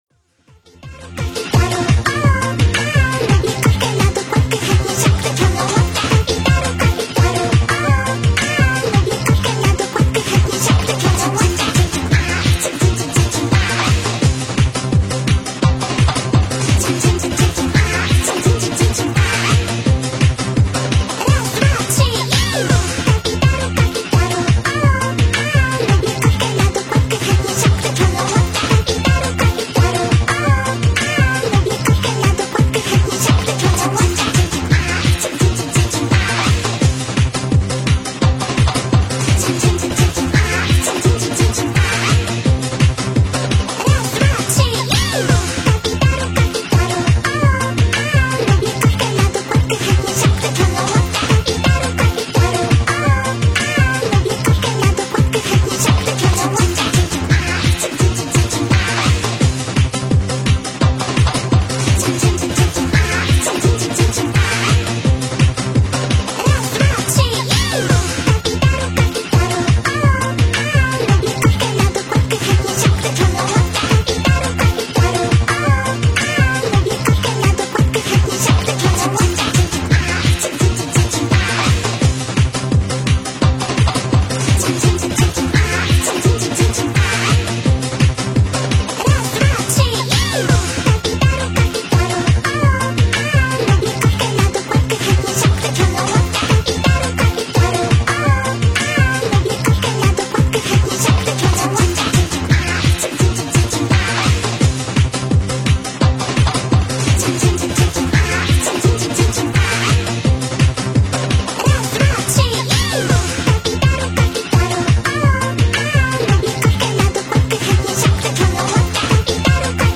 ciamkanie_lofi.m4a